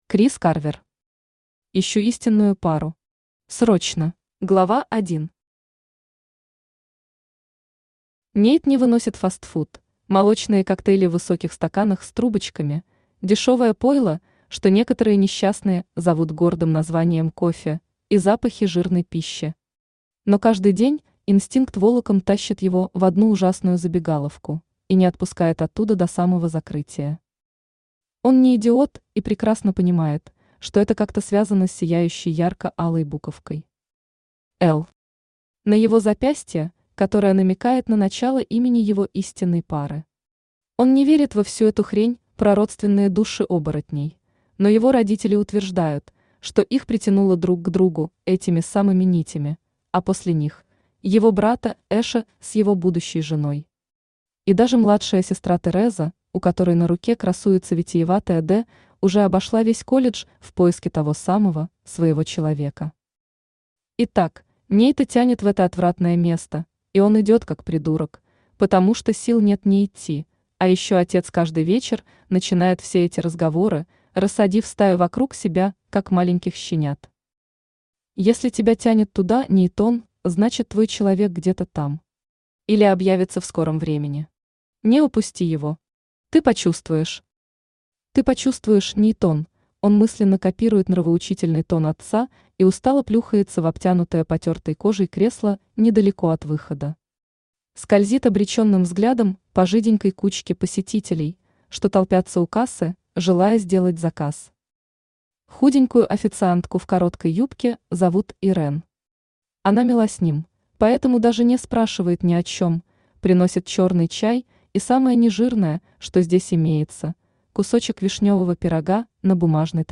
Аудиокнига Ищу истинную пару.
Срочно Автор Крис Карвер Читает аудиокнигу Авточтец ЛитРес.